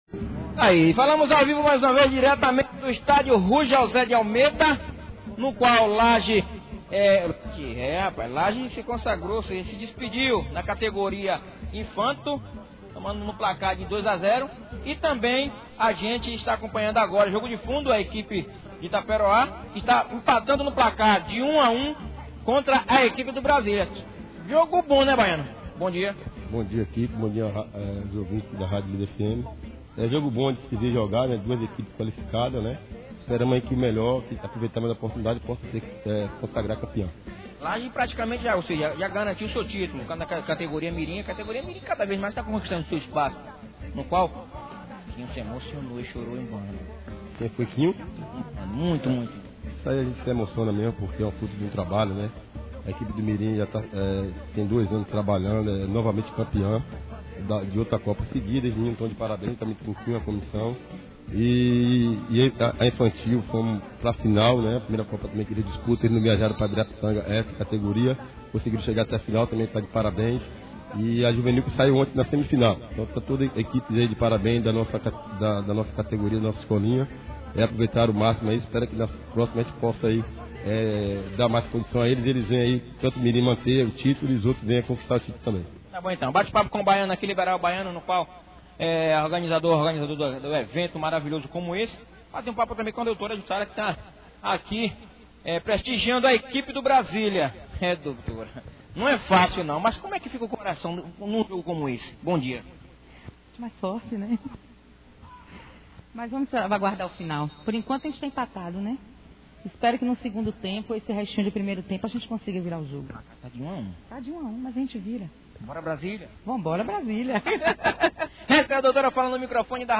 Dirigentes, atletas, o prefeito de Laje, Emiran, o secretário Lindomar Ferreira, de administração e Indiamara Alves, da Educação além dos vereadores: Tany Motos e Helenildo Souza, que participaram da cerimonia de encerramento e premiação em entrevista a equipe de reportagem da Líder FM/Laje falaram sobre a importância do evento.